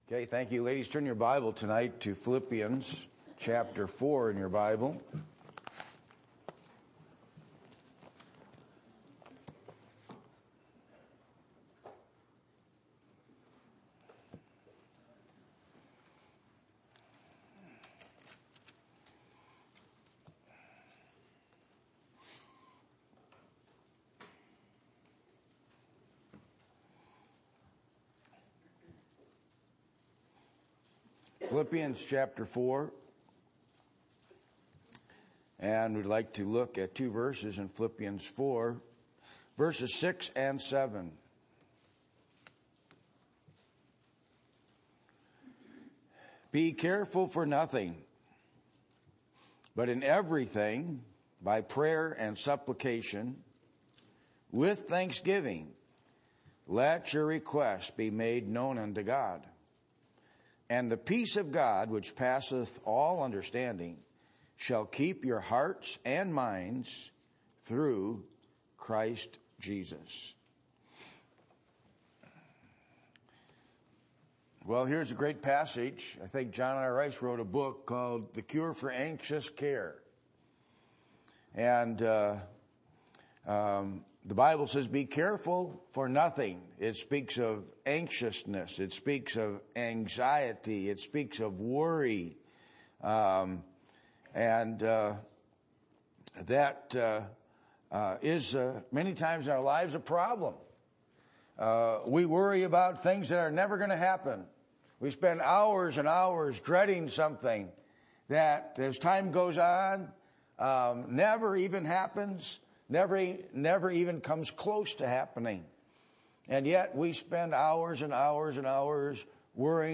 Passage: Philippians 4:6-7 Service Type: Sunday Evening %todo_render% « The Supernatural Deliverance of God’s People Have You Forgotten God?